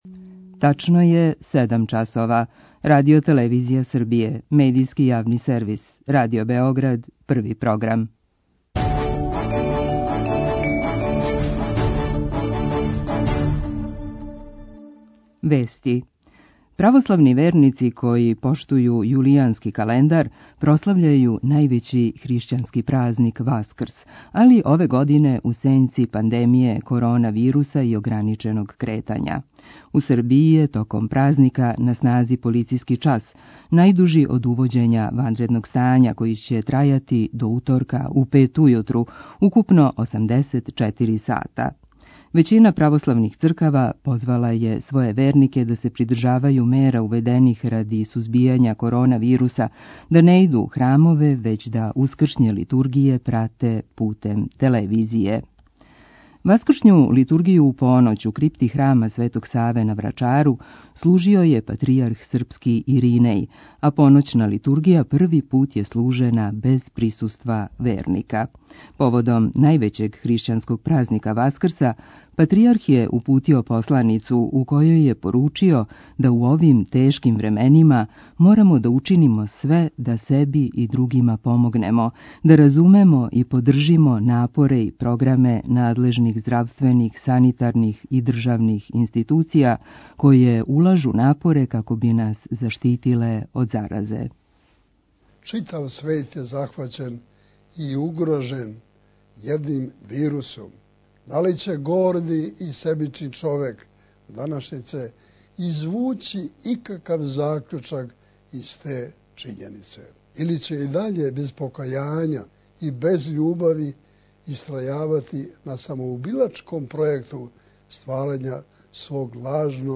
Разговараћемо с једним од организатора чувене мокринске "Туцанијаде" која први пут ове године неће бити одржана због ванредног стања. Чућемо и како актуелно стање у нашој земљи утиче на Роме.